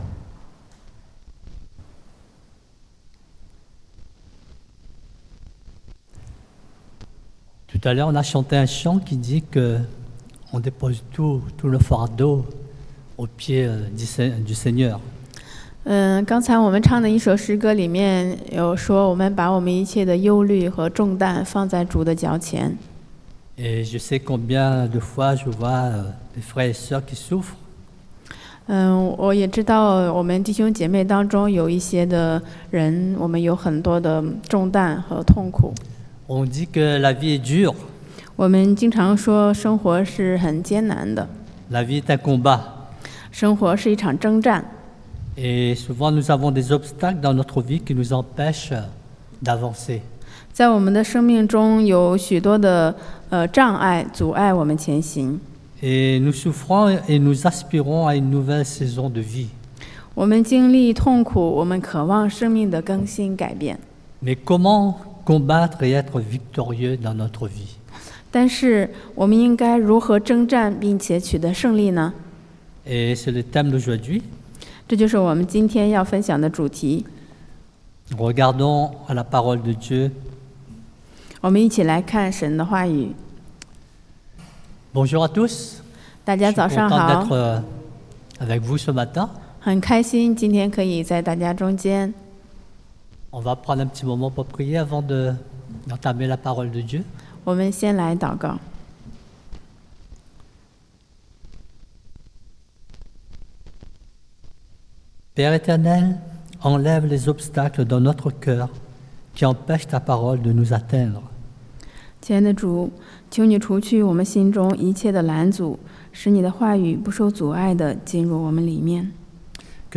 Passage: Josué 约书亚记 3 : 1-17 Type De Service: Predication du dimanche